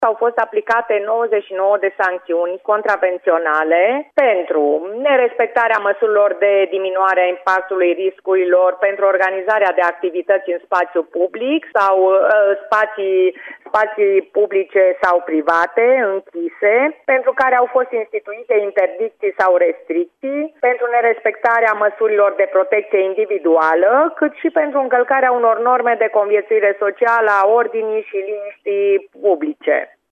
Prefectul Liliana Oneț spune că cele mai multe sancțiuni au fost date patronilor de terase și localuri în care numărul clienților a fost mai mare decât se recomandă în această perioadă, nerespectându-se regulile de distanțare socială și igienă: